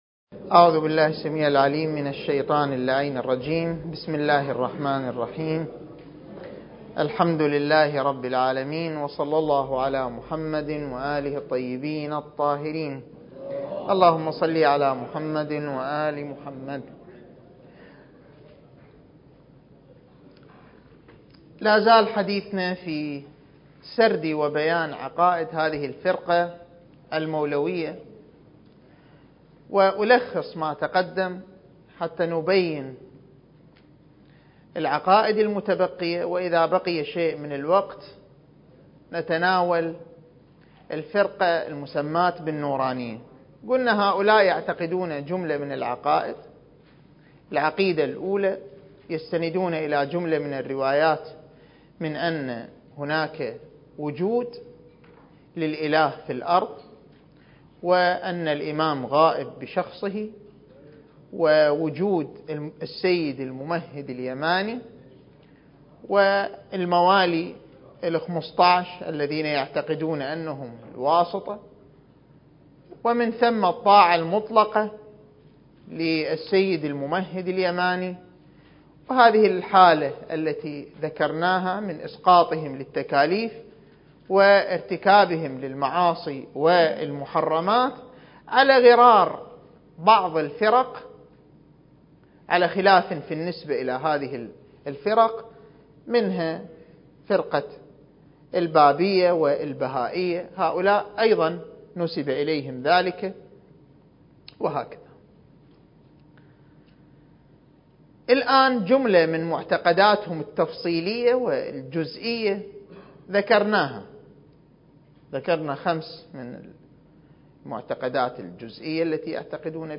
المكان: مؤسسة الإمام الحسن المجتبى (عليه السلام) - النجف الأشرف دورة منهجية في القضايا المهدوية (رد على أدعياء المهدوية) (16) التاريخ: 1443 للهجرة